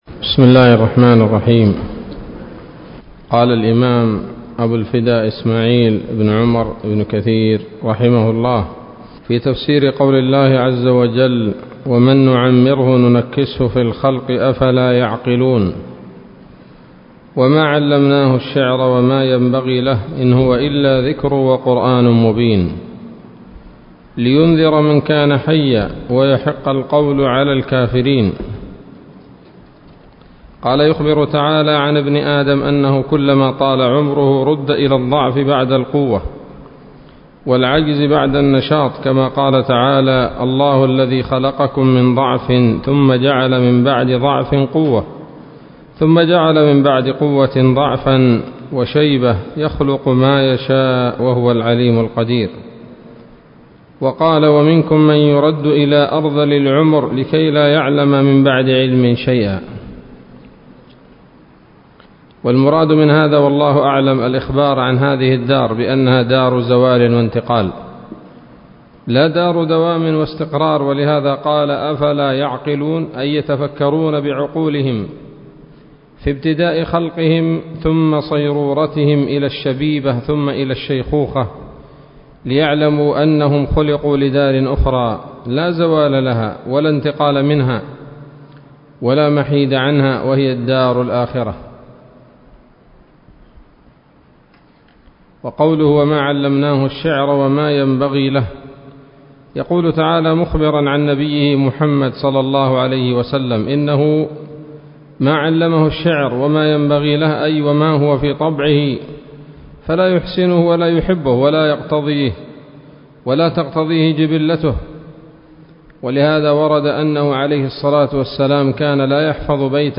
الدرس الثالث عشر من سورة يس من تفسير ابن كثير رحمه الله تعالى